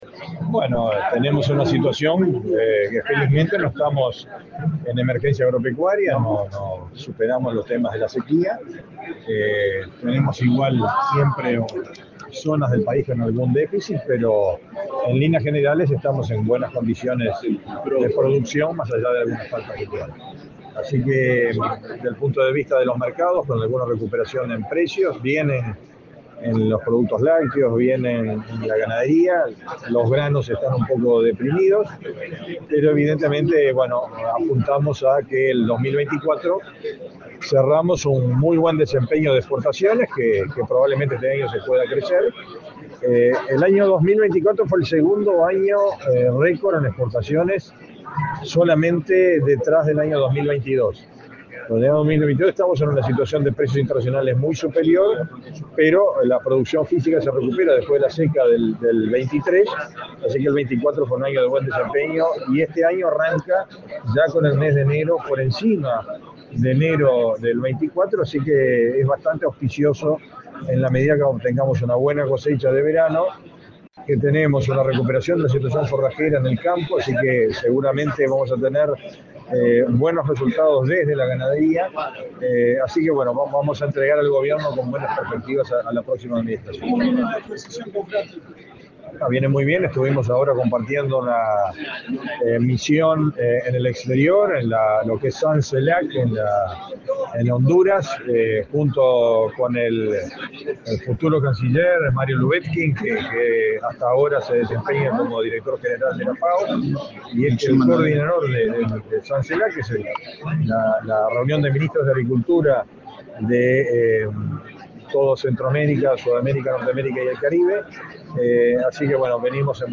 Declaraciones a la prensa del ministro de Ganadería, Agricultura y Pesca, Fernando Mattos
Tras participar en la apertura del foro Uruguay Sostenible en la exposición Agro en Punta, en el Centro de Convenciones de Punta del Este, este 5de
mattos prensa.mp3